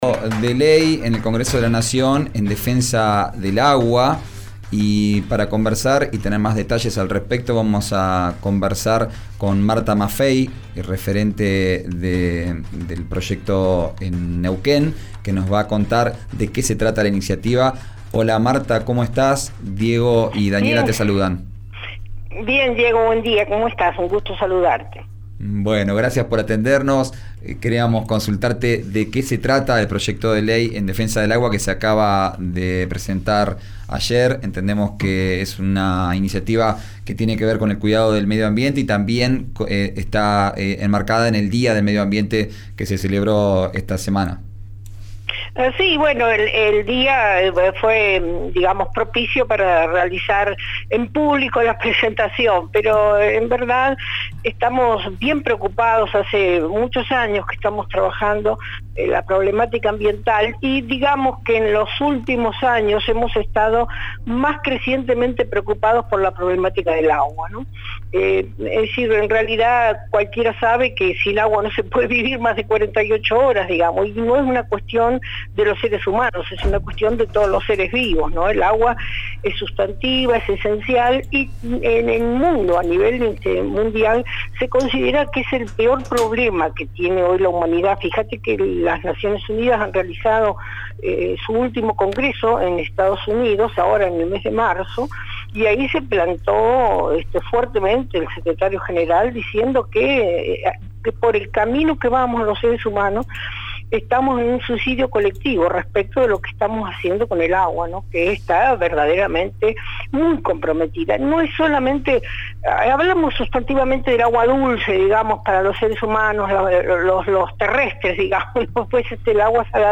En conversación con RÍO NEGRO RADIO, señaló que los seres humanos van hacia un «suicidio colectivo» si no resuleven la problemática del agua, tanto dulce como salada.